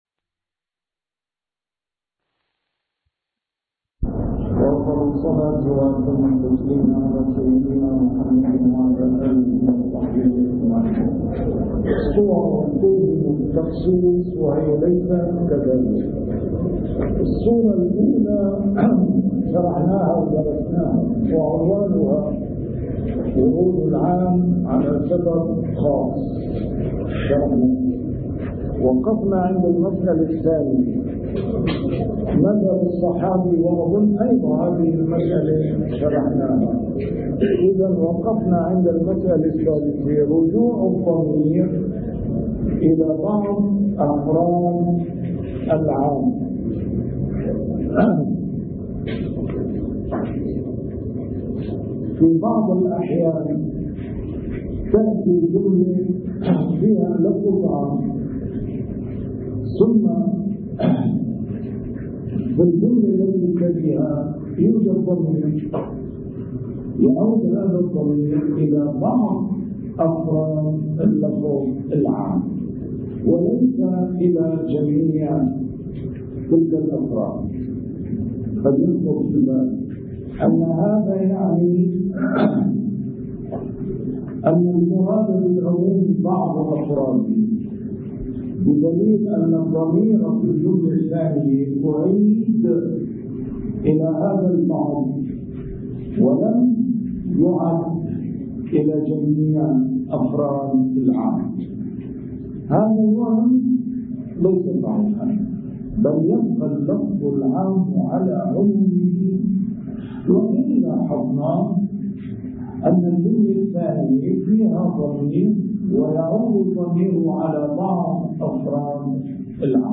A MARTYR SCHOLAR: IMAM MUHAMMAD SAEED RAMADAN AL-BOUTI - الدروس العلمية - مباحث الكتاب والسنة - الدرس العشرون: أنواع البيان [تخصيص العام وتقييد المطلق وتأويل الظاهر]